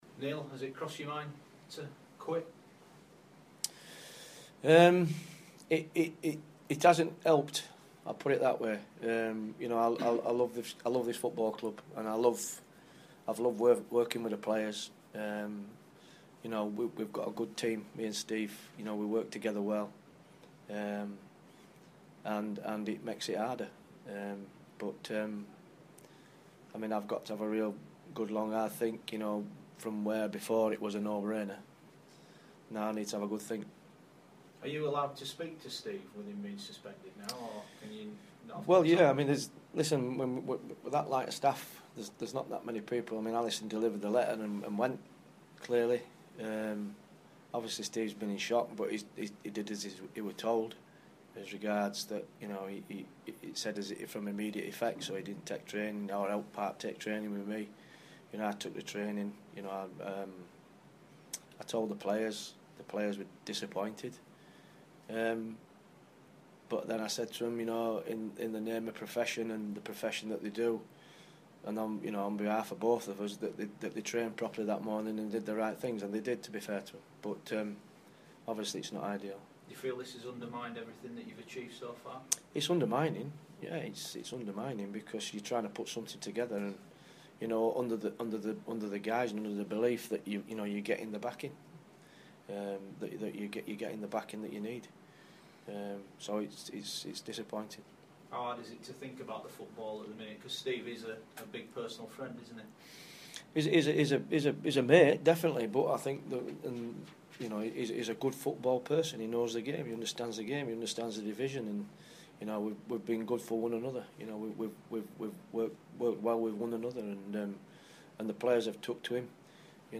Neil Redfearn's extended interview about his future at Leeds United and Steve Thompson's situation at the club